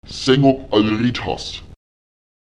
Die Betonung verlagert sich auf die vorletzte Silbe.